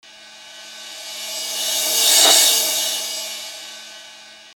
金属钹回声
描述：在FL中制作，给一个钹声添加混响，延迟。
标签： 延迟 强大 碰撞 混响 回声
声道立体声